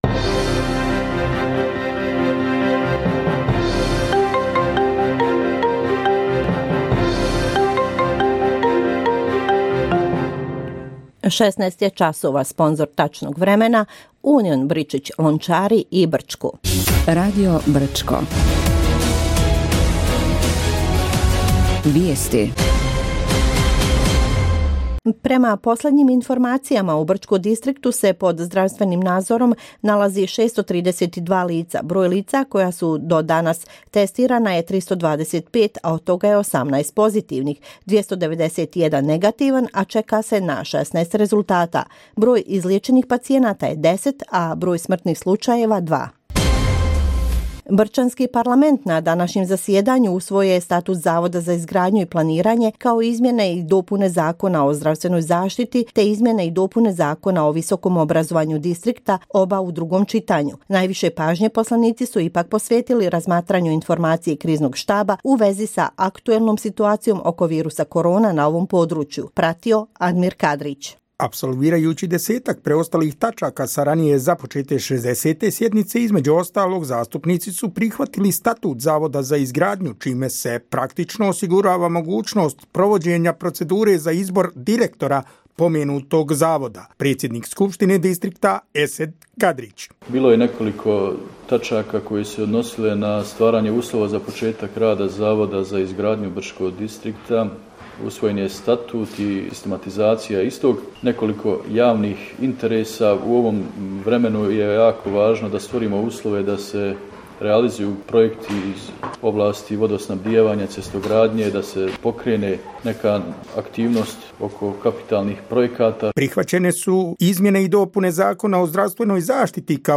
Radio Brčko: Vijesti za srijedu 22.04.2020. godine